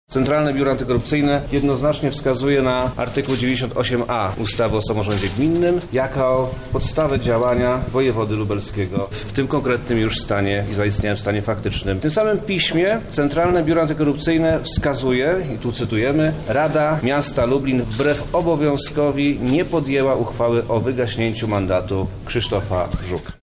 Wojewoda Lubelski zasięgnie opinii prawników w sprawie prezydenta Krzysztofa Żuka. Podczas czwartkowej konferencji Przemysław Czarnek wygłosił swoje stanowisko.
– mówi Przemysław Czarnek, Wojewoda Lubelski.